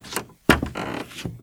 High Quality Footsteps / Wood / Wood, Creaky
MISC Wood, Foot Scrape 01.wav